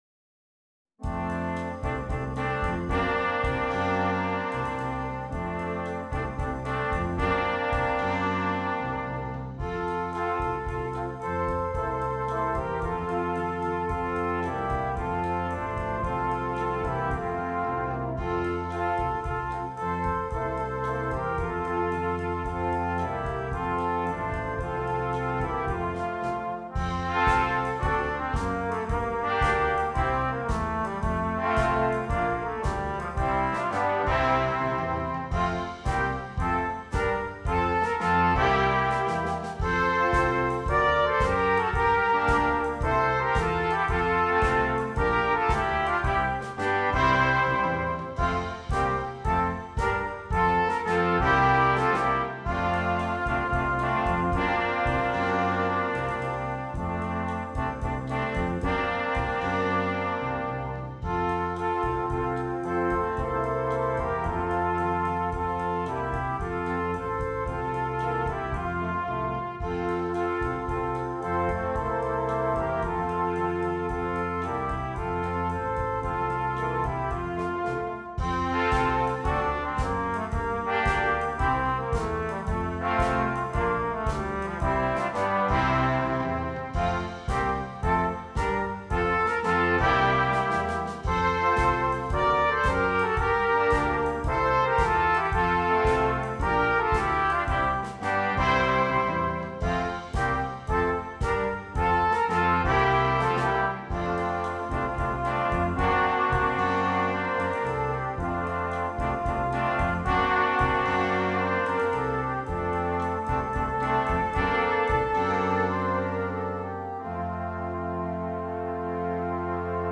Brass Quintet (optional Drum Set)
Traditional Carol